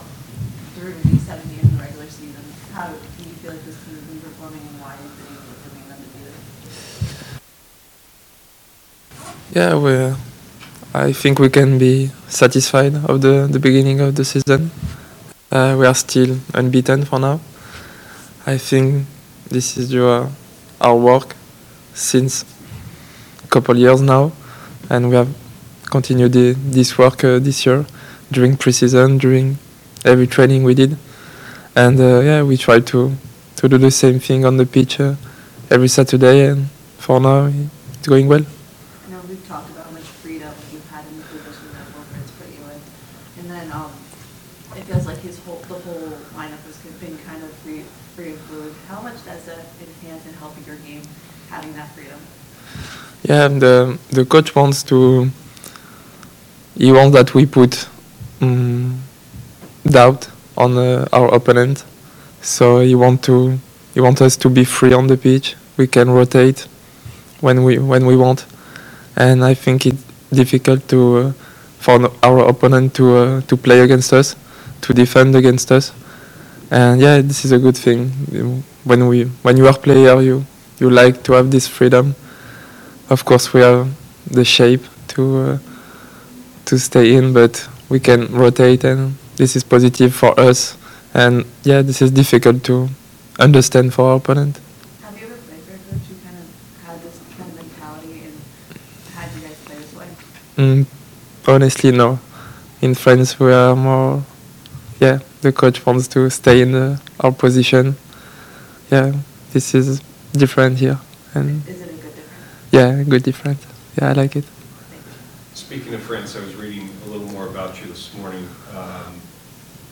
Dylan Chambost talking to media previewing St. Louis City for April 12, 2025 (courtesy Crew Communications)